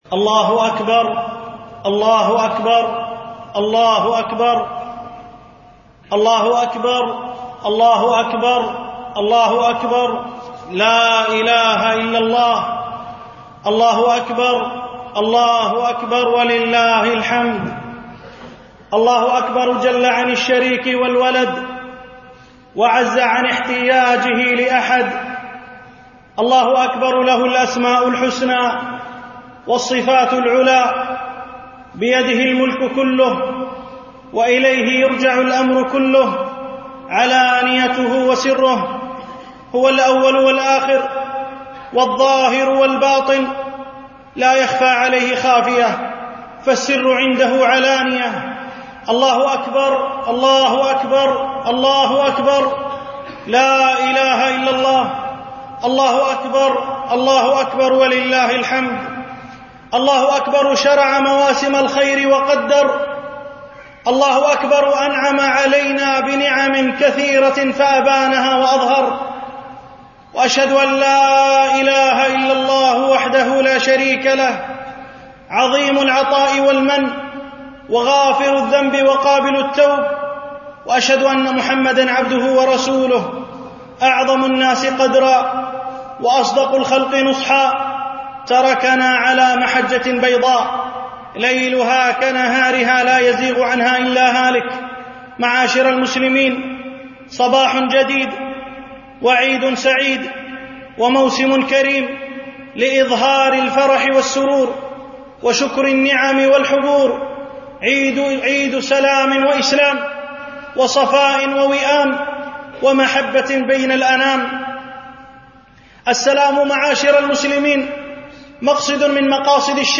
خطبة عيد الفطر 1437